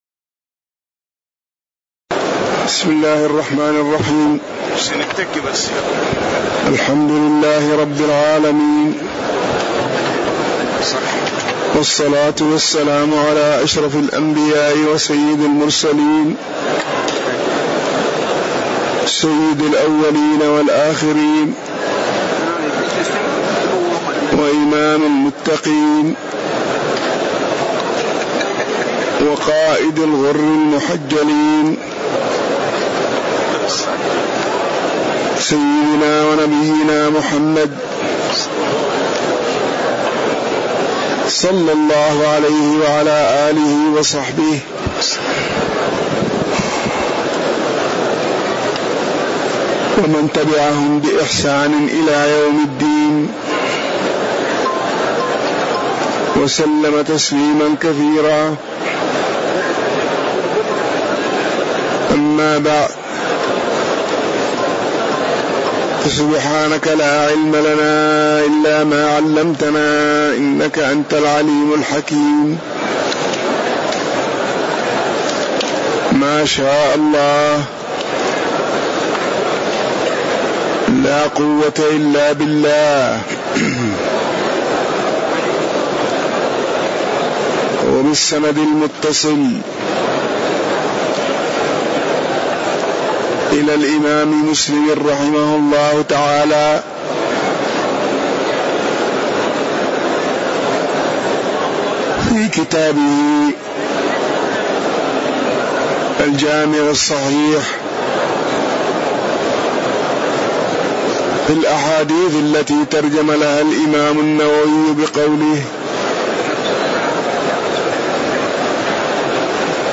تاريخ النشر ٢٣ جمادى الأولى ١٤٣٨ هـ المكان: المسجد النبوي الشيخ